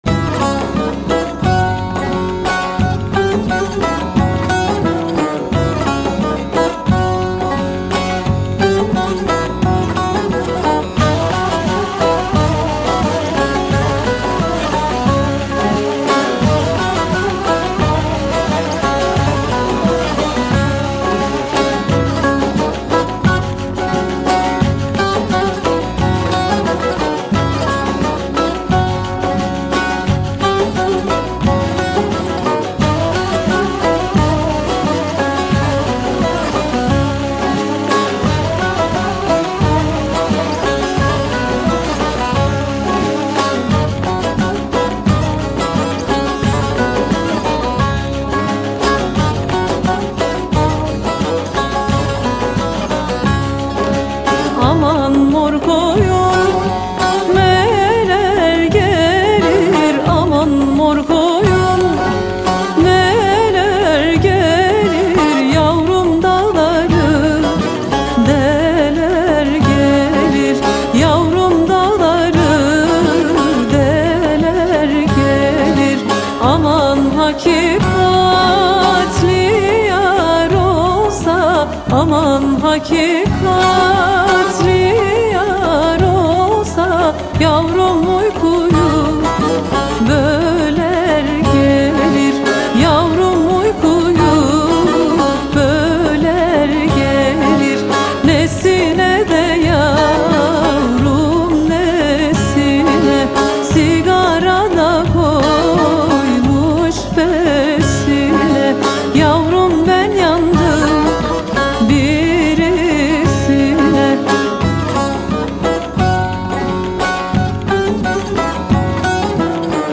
Etiketler: ankara, türkü, müzik, türkiye